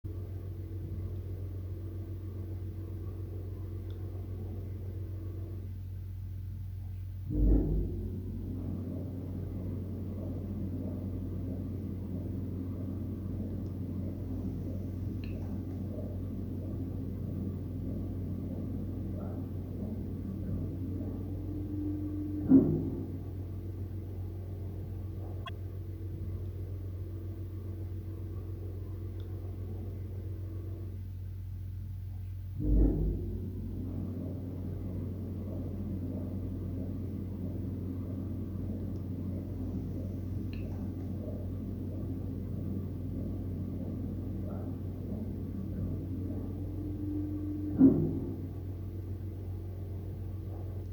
На тот момент мне сложно было судить, является ли данный шум существенным, или это нормально, но субъективно шум был громким, ярко выраженным.
Звук немного изменился, но существенных отличий нет:
шум от лифта до работ